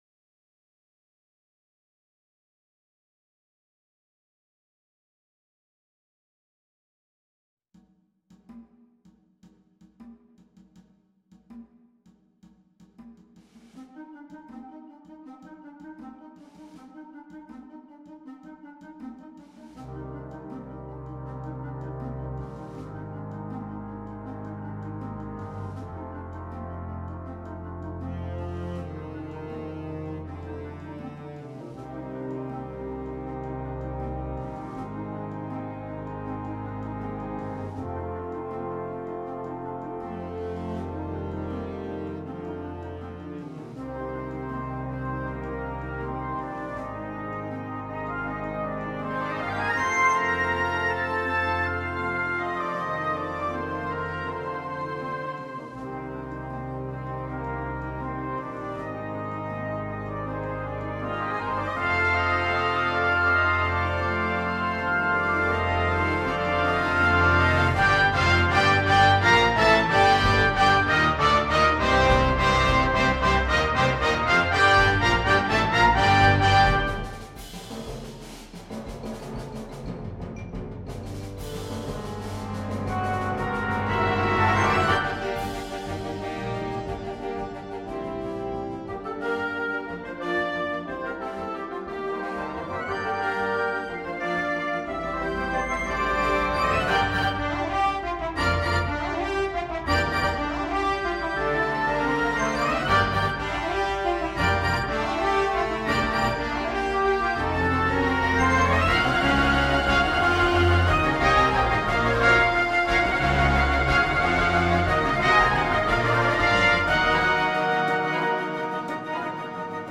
Concert Band
Blasorchester